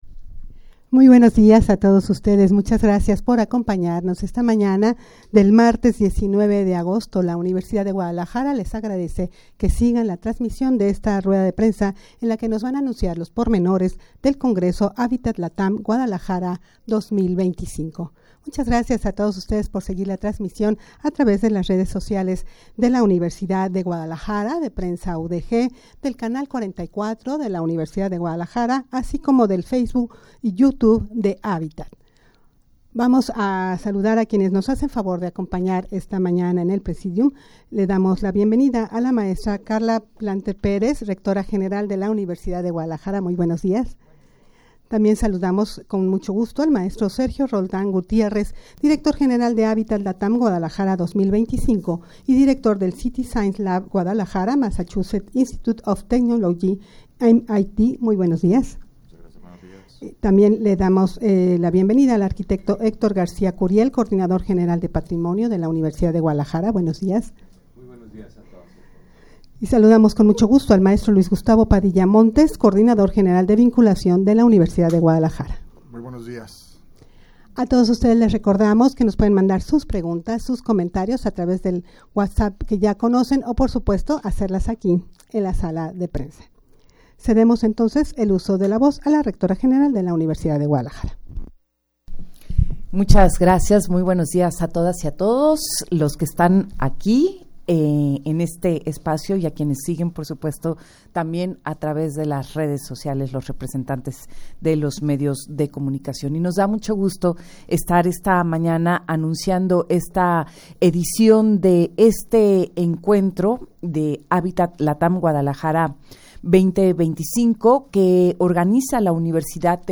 Audio de la Rueda de Prensa
rueda-de-prensa-para-anunciar-los-pormenores-del-congreso-habitat-latam-guadalajara-2025.mp3